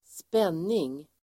Uttal: [²sp'en:ing]